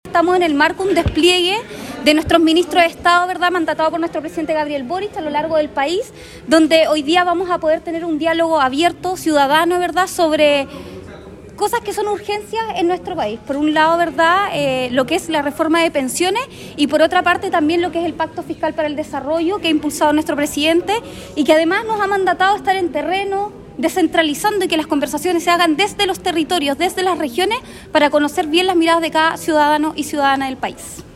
Ministros del Trabajo y Hacienda expusieron en el Teatro Municipal de Osorno
La Delegada Presidencial Regional, Giovanna Moreira destacó el despliegue que han realizado los jefes de las carteras de Hacienda y Trabajo, para de esta formar despejar las dudas de las personas que viven en los distintos territorios de nuestro país.